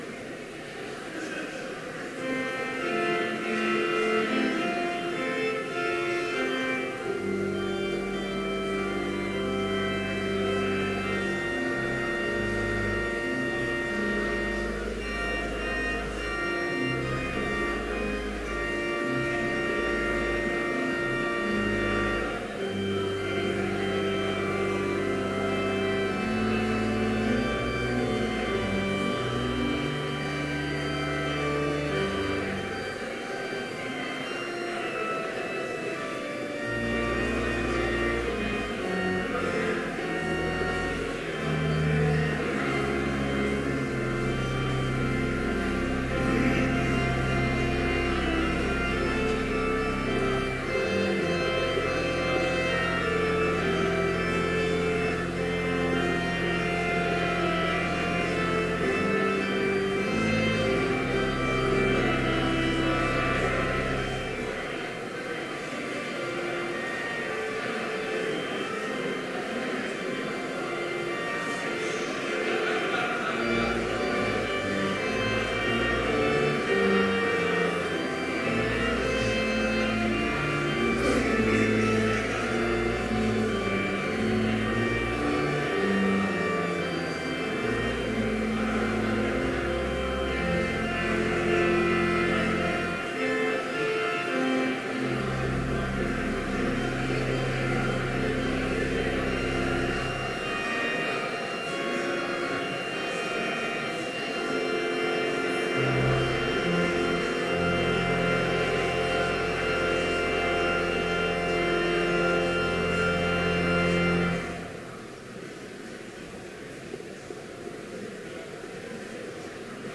Complete service audio for Chapel - January 12, 2015